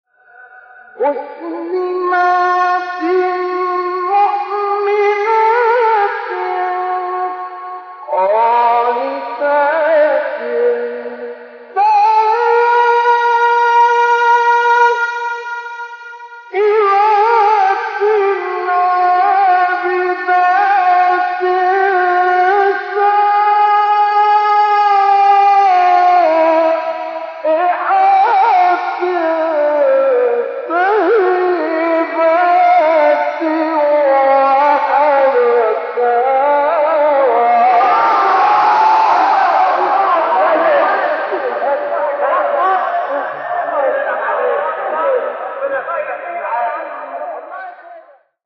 سوره: تحریم آیه: 5 استاد: مصطفی اسماعیل مقام:‌ رست قبلی بعدی